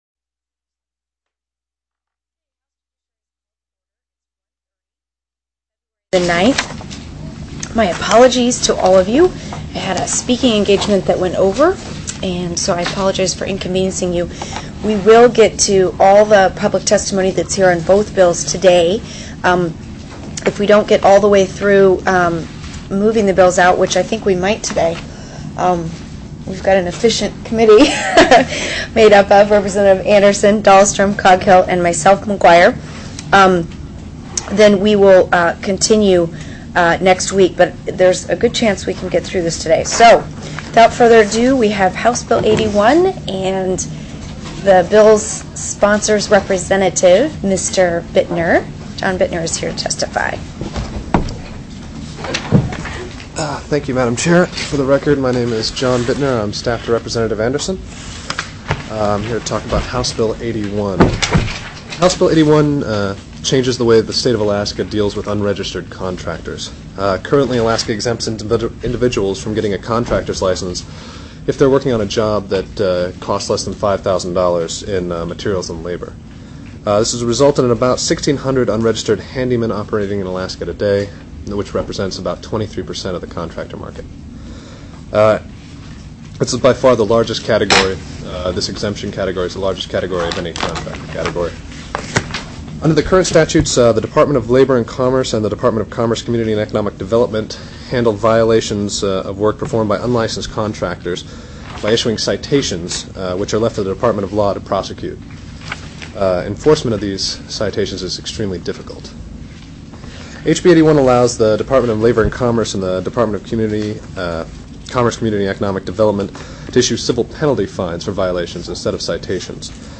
02/09/2005 01:00 PM House JUDICIARY